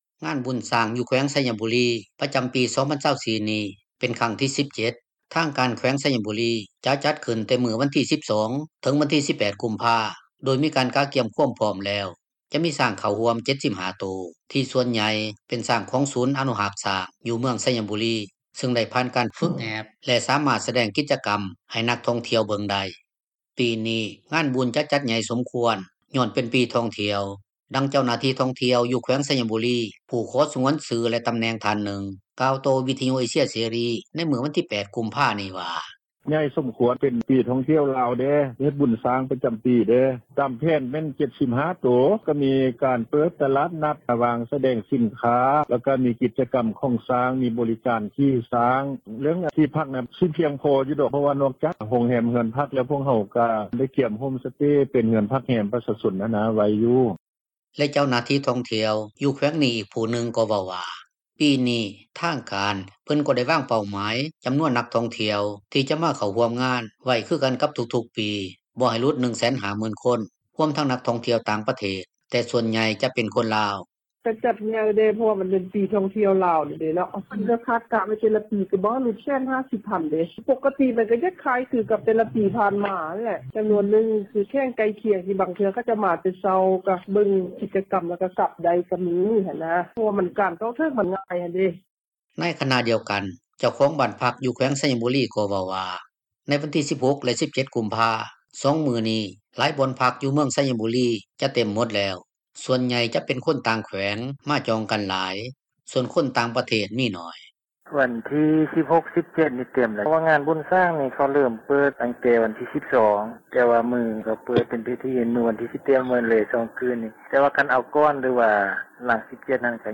ດັ່ງເຈົ້າໜ້າທີ່ ທ່ອງທ່ຽວ ຢູ່ແຂວງໄຊຍະບູຣີ ຜູ້ຂໍ ສງວນຊື່ ແລະຕໍາແໜ່ງທ່ານນຶ່ງ ກ່າວຕໍ່ວິທຍຸເອເຊັຽເສຣີ ໃນມື້ວັນທີ 8 ກຸມພານີ້ວ່າ: